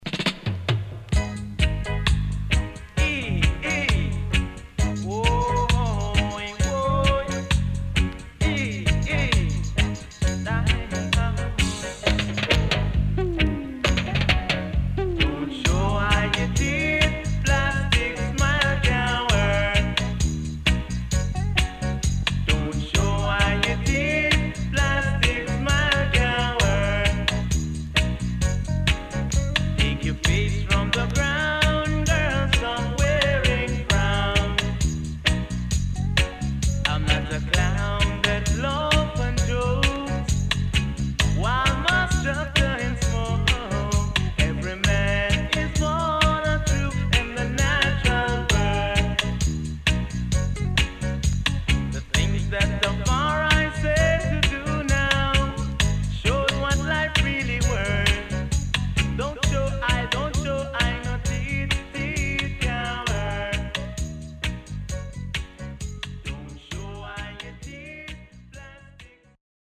SIDE B:少しノイズ入りますが良好です。